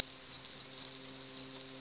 sounds / animals / robin22.wav
robin22.mp3